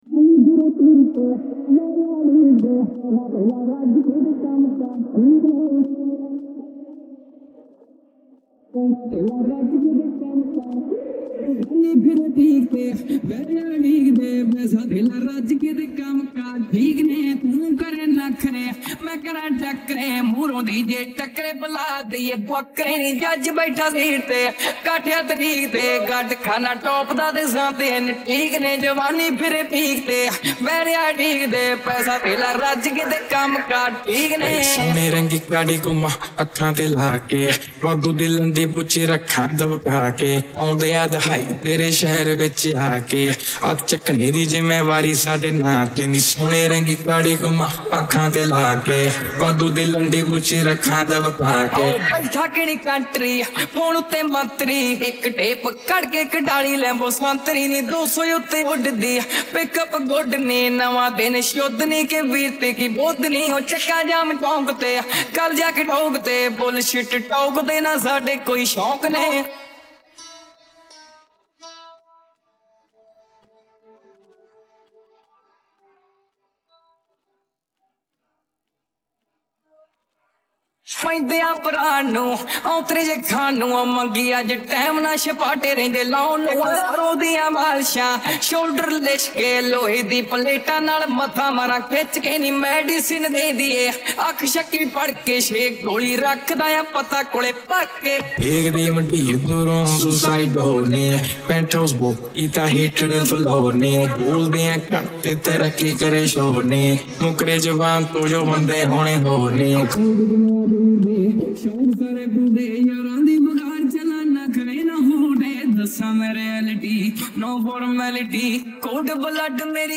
Vocal Part